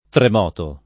tremoto [ trem 0 to ]